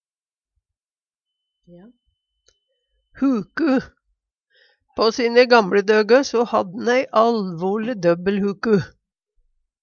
huku - Numedalsmål (en-US)